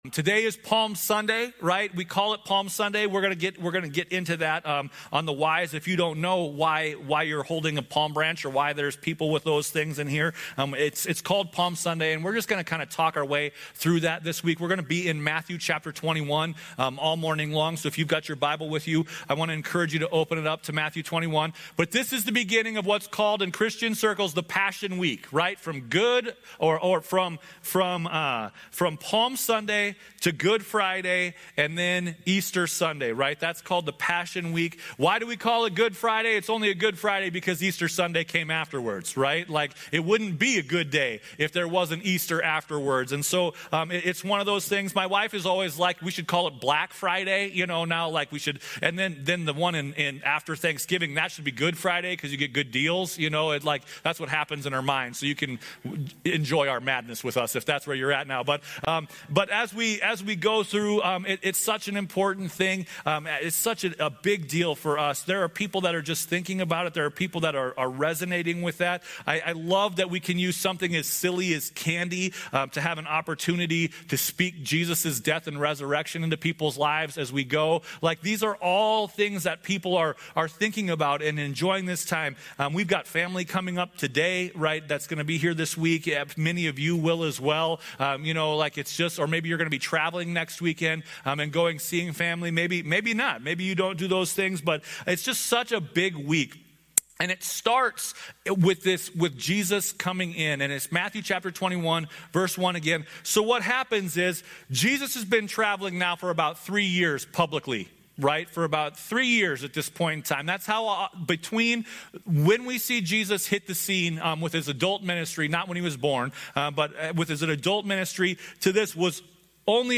Some sermons that aren't in a normal series